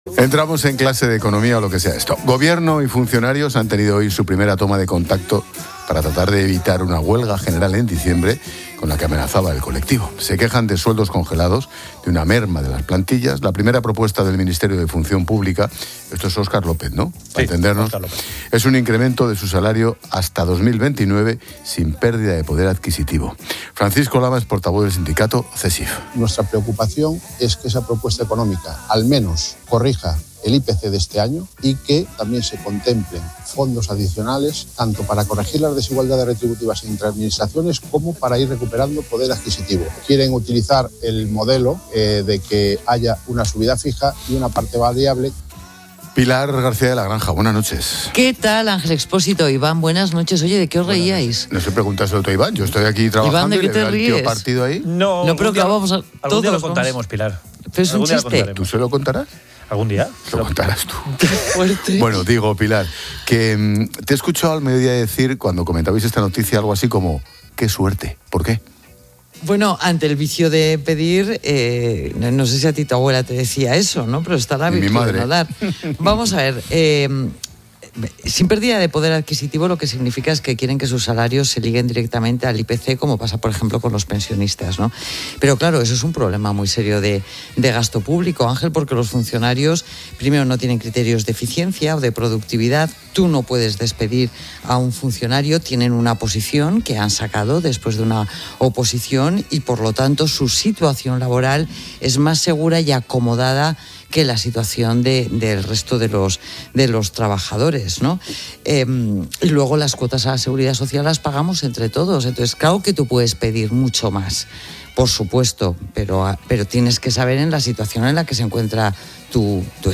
Ángel Expósito y Pilar García de la Granja, experta económica y directora de Mediodía COPE, analizan la subida de salario a los funcionarios